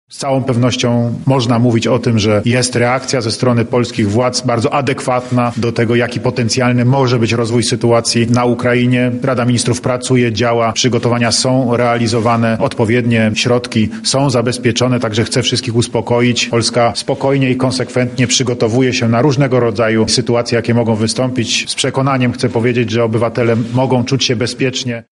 • mówi prezydent Andrzej Duda.